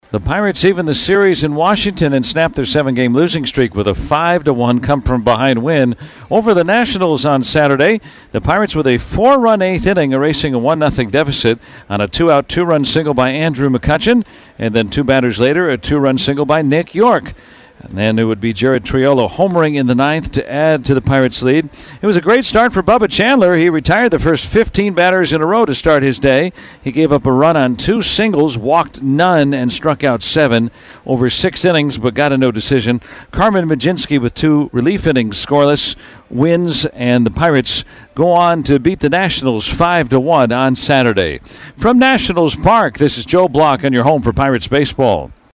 9-13-recap-1.mp3